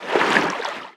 Sfx_creature_titanholefish_swim_04.ogg